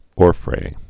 (ôrfrā)